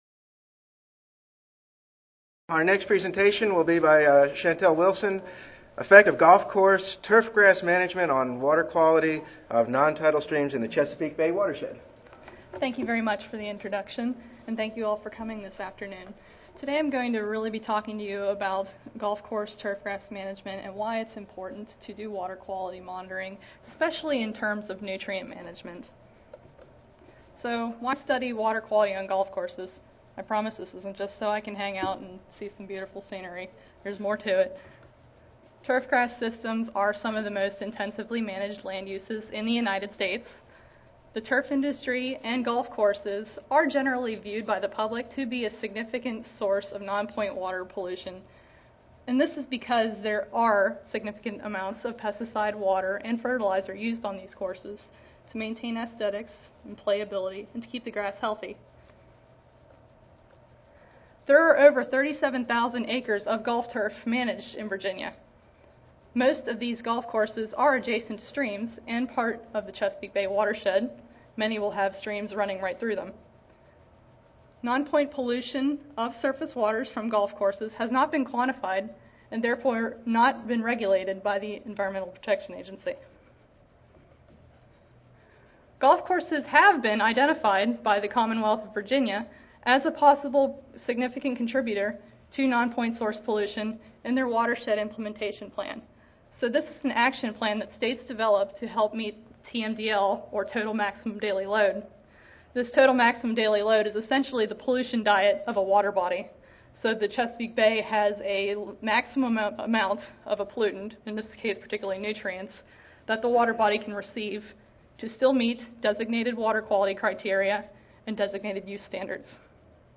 See more from this Division: C05 Turfgrass Science See more from this Session: Ecology, Water, Soil, Cultural and Pest Management: Student Oral Competition